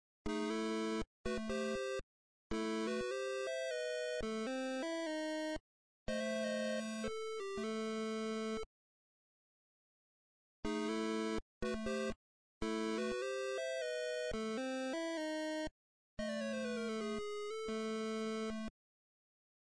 オープニングテーマがあり、飛びぬけて名曲というわけでもないが何か探索心をくすぐられる気分にさせられて好きだ。